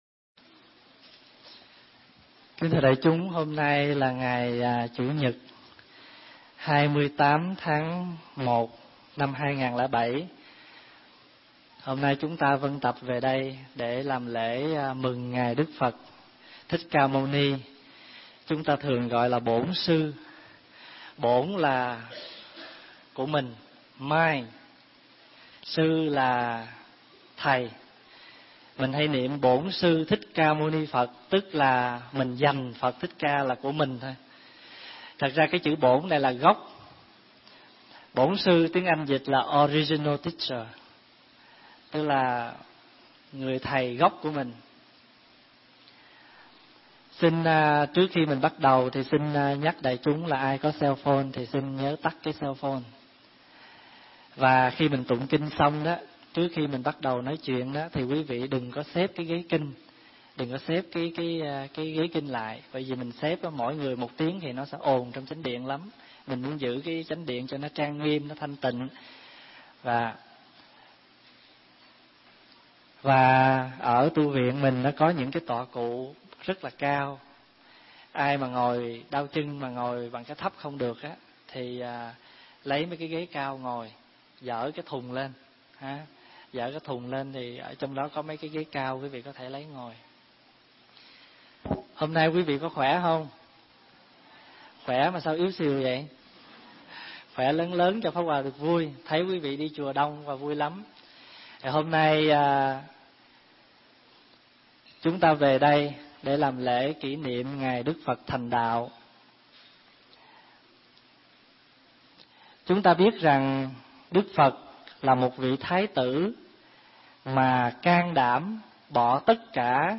Tải mp3 Thuyết Giảng Tu Là Khéo Thêm Khéo Bớt
thuyết giảng tại Tu Viện Trúc Lâm, Canada